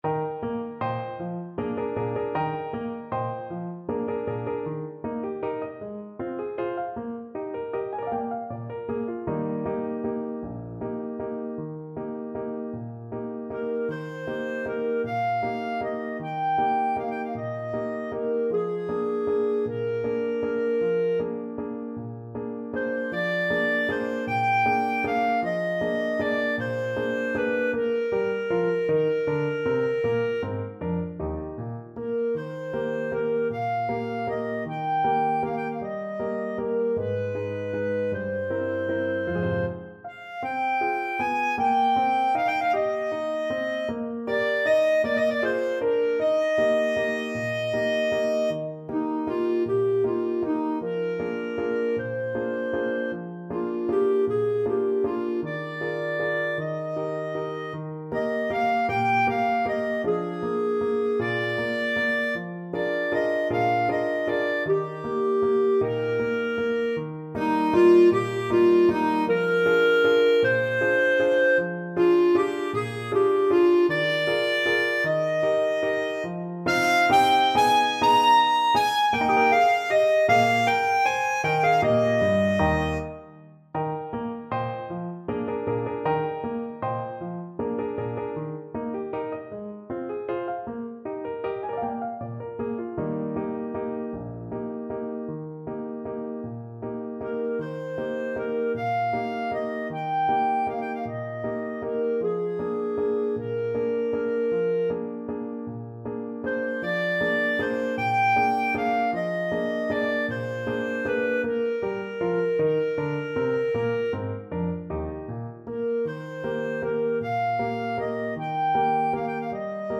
ClarinetPiano
Allegretto . = c.52
6/8 (View more 6/8 Music)
Eb5-Bb6
Clarinet  (View more Intermediate Clarinet Music)
Classical (View more Classical Clarinet Music)